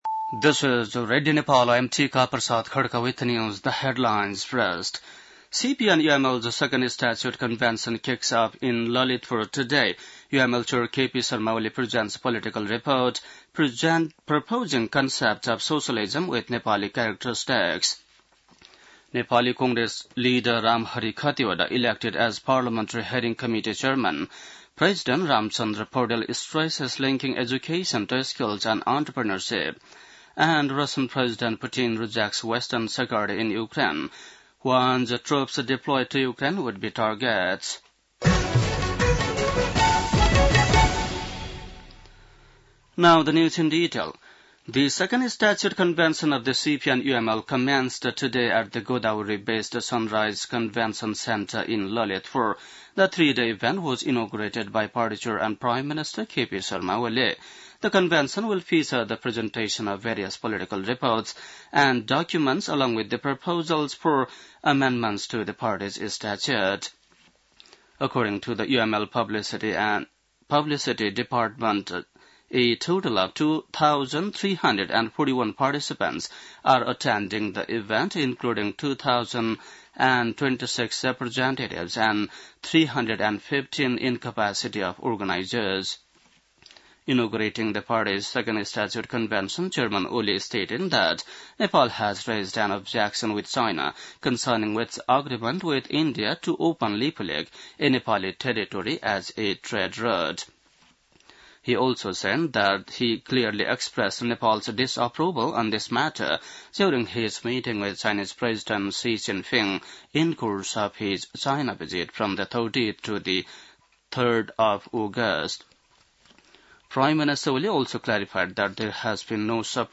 बेलुकी ८ बजेको अङ्ग्रेजी समाचार : २० भदौ , २०८२
8-pm-English-news-5-20.mp3